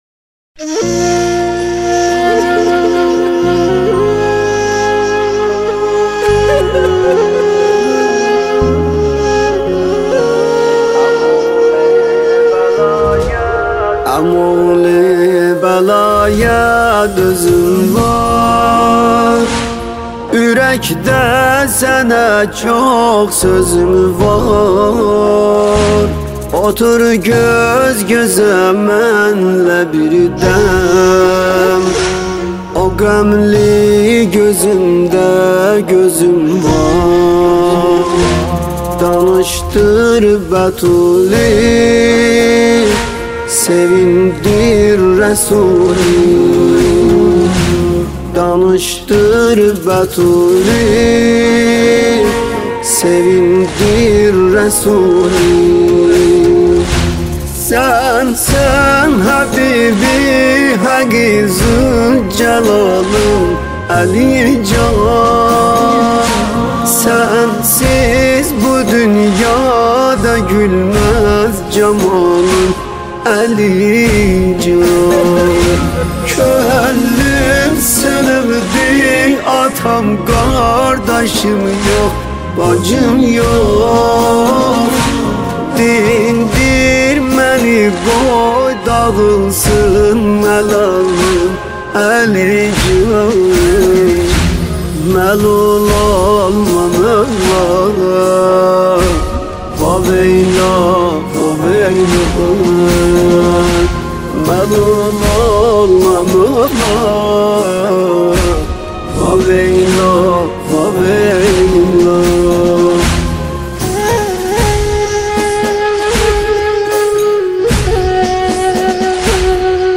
مداحی ترکی